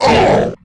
nemesis_hurt_01.mp3